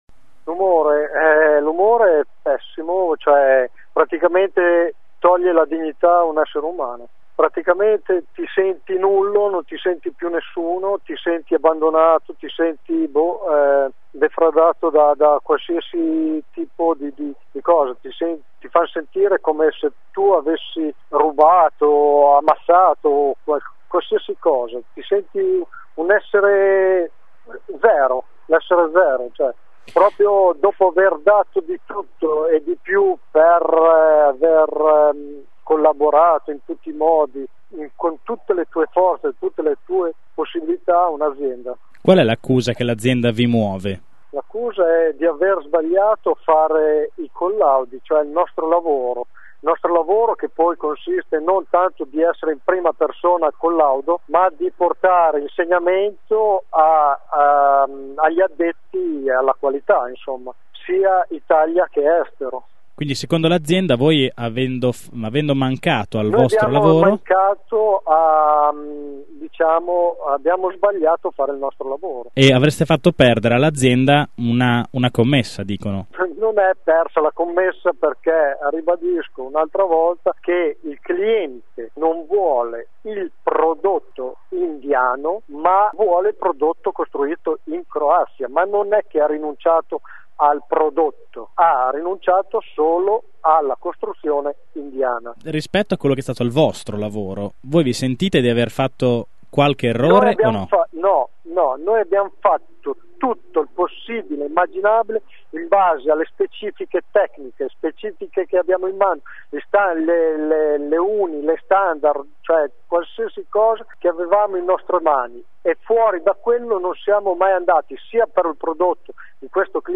Le uniche parole che ci sembra sensato riproporvi sono quelle che abbiamo raccolto, a caldo, da uno dei lavoratori licenziati e che già avete sentito nei giornali radio.
lavoratore_ducati.mp3